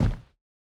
added stepping sounds
BootsLinoleum_04.wav